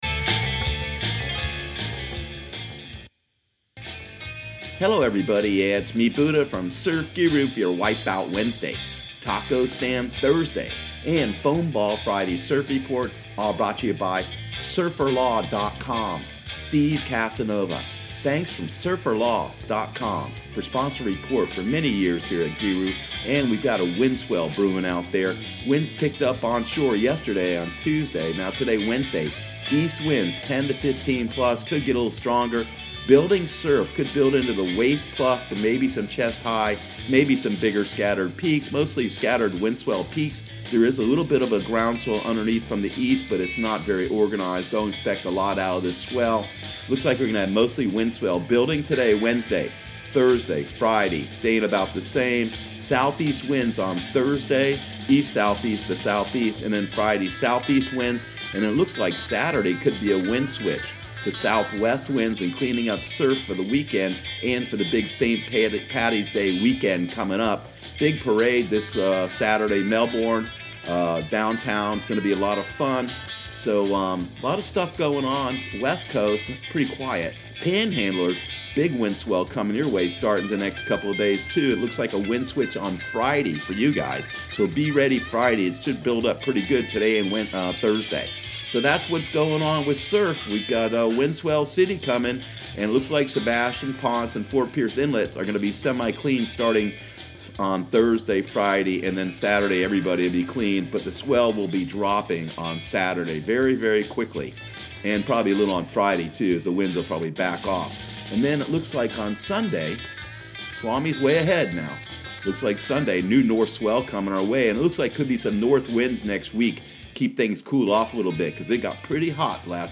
Surf Guru Surf Report and Forecast 03/13/2019 Audio surf report and surf forecast on March 13 for Central Florida and the Southeast.